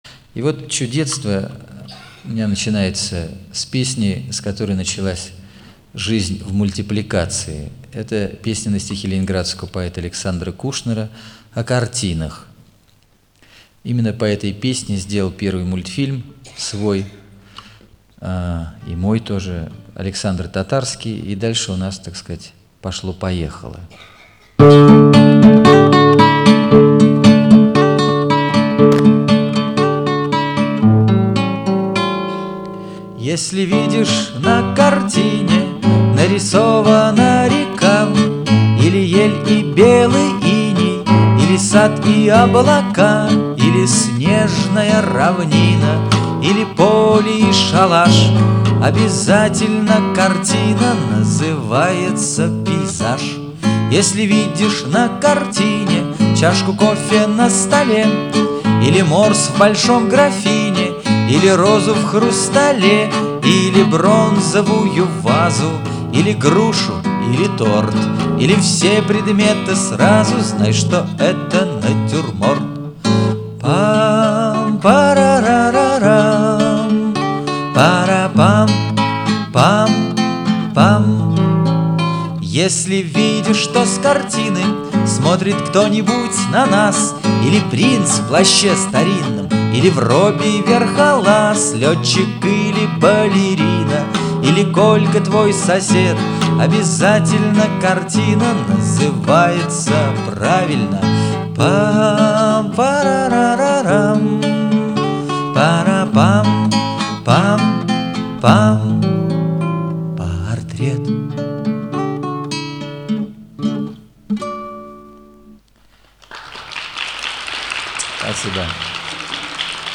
музыка и исполнение